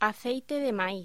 Locución: Aceite de maíz